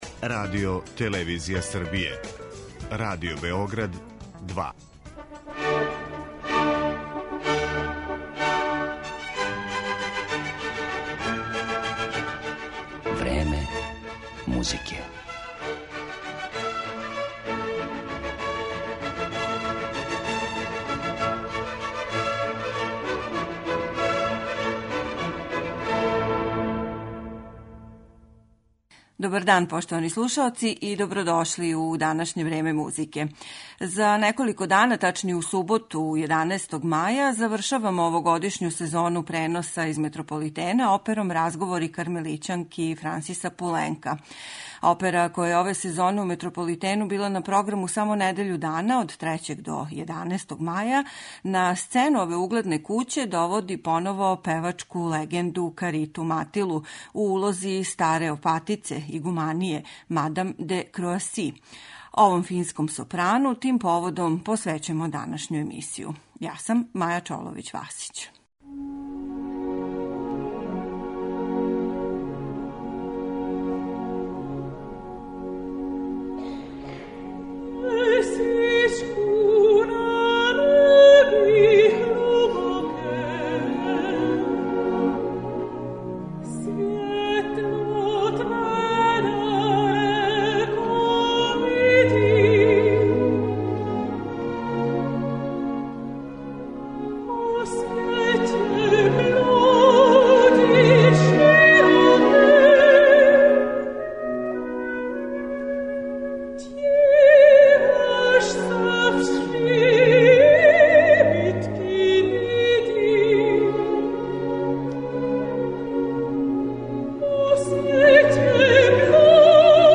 Фински сопран Карита Матила.